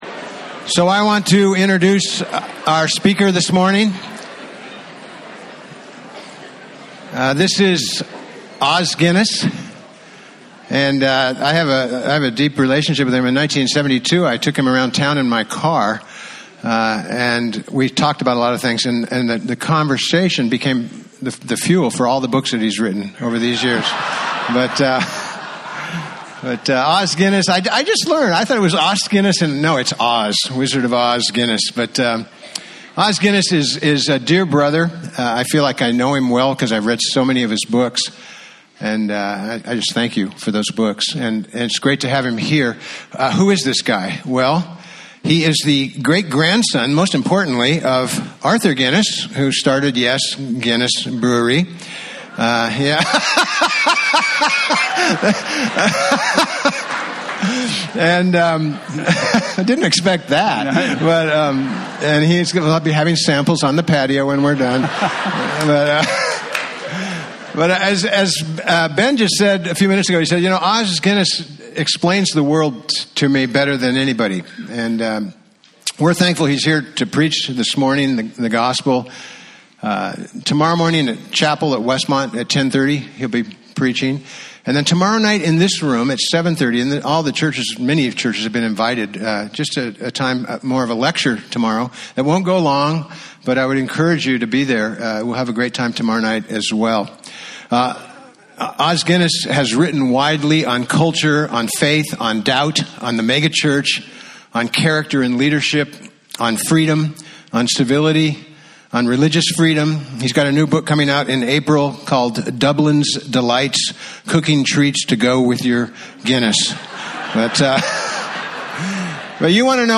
Preacher: Guest Preacher…